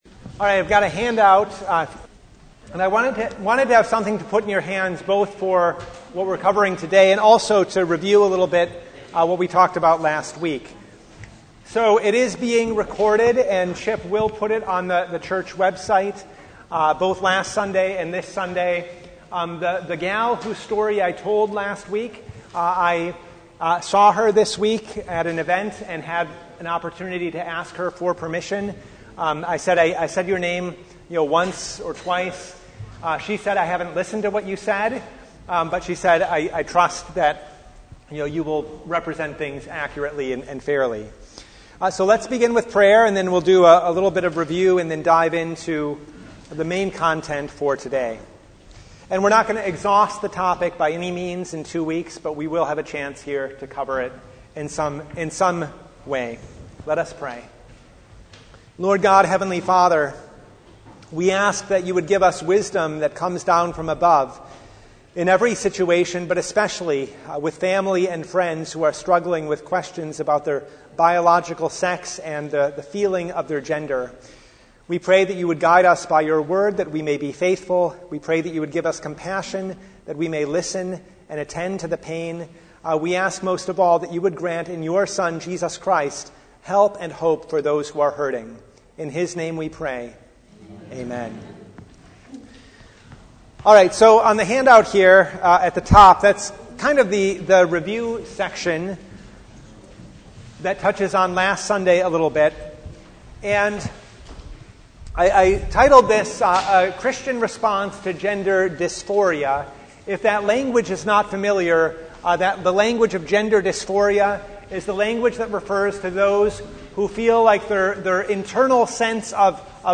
Service Type: Bible Hour
Topics: Bible Study